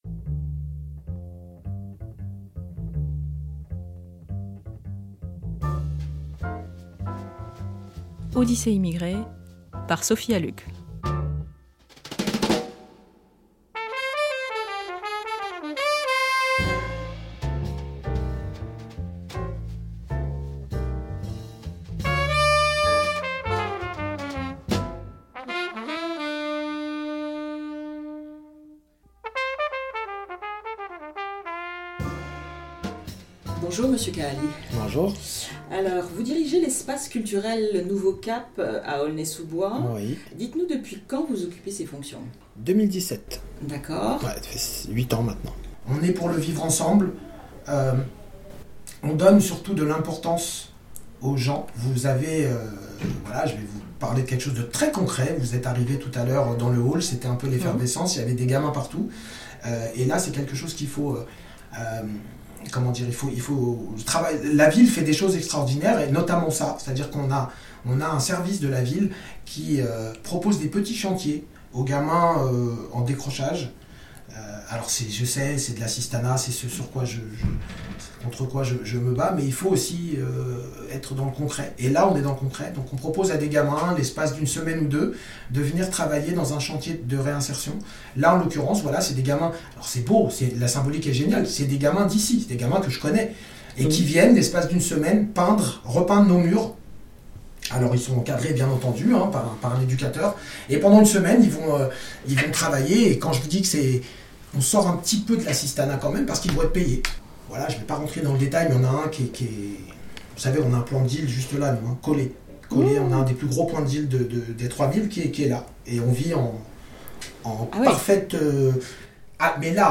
Deuxième partie de l’entretien